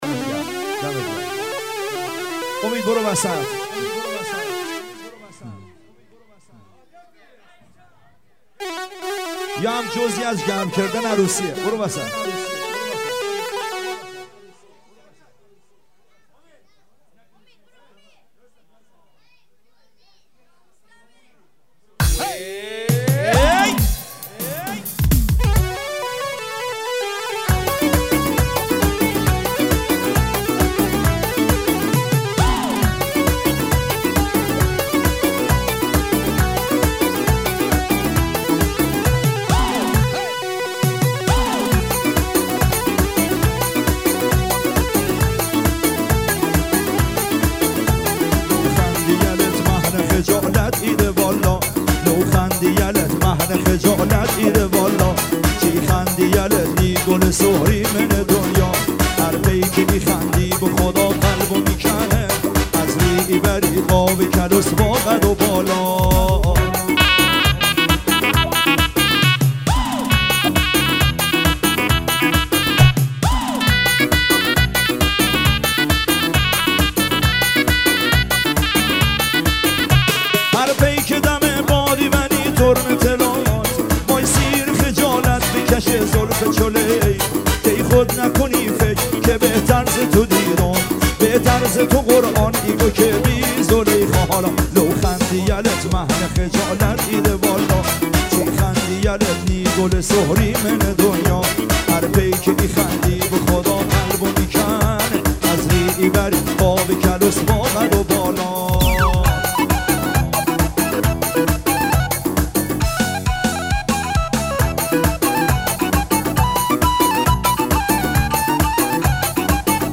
دانلود آهنگ لری